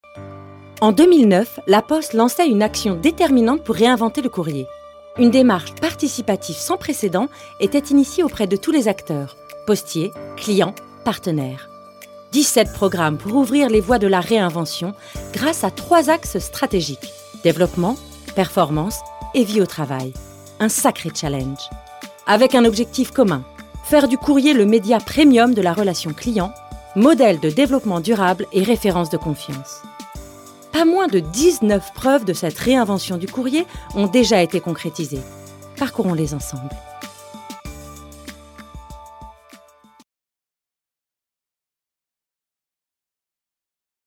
FILMS INSTITUTIONNELS